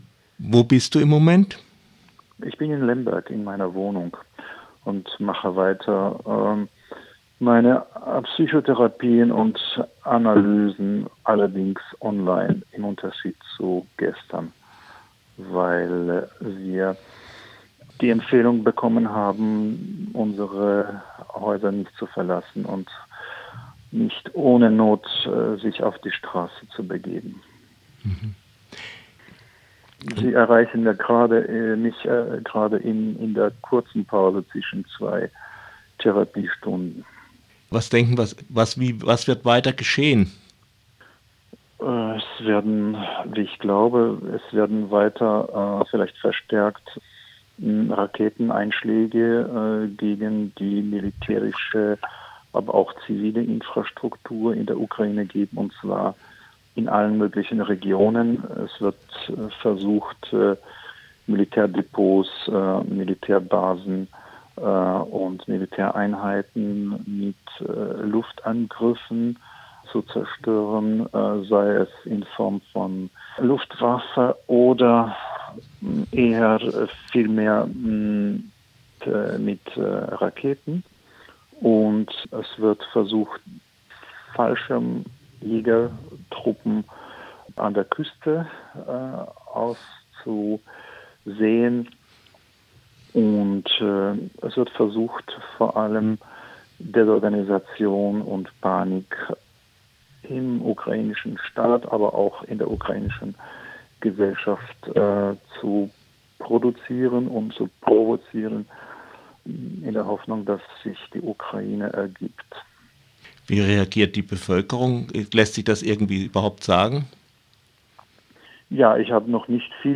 Wenige Stunden nach dem russischen Überfall auf die Ukraine telefonierte Radio Dreyeckland mit dem Therapeuten